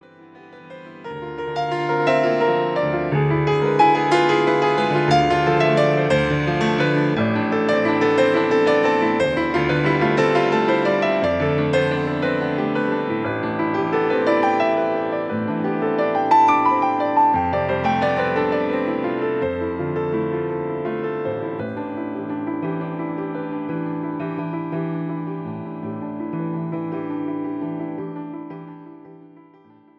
Piano Solo Version